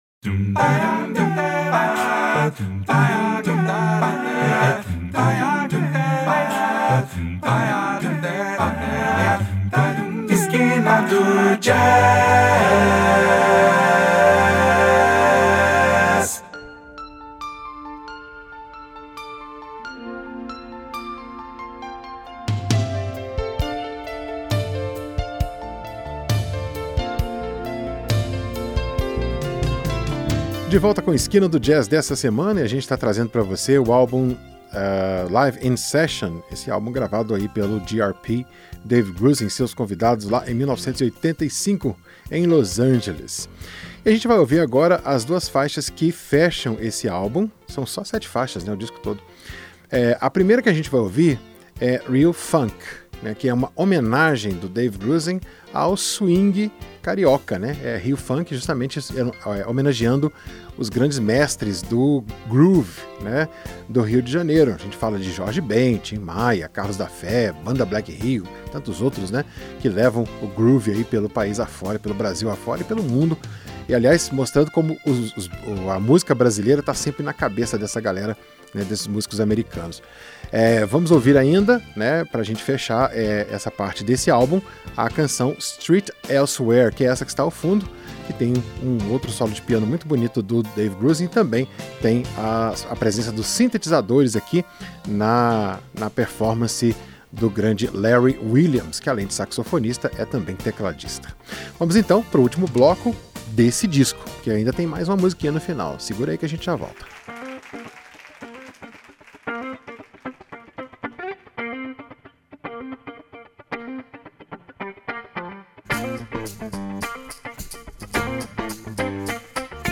gravado em Los Angeles e lançado em 1985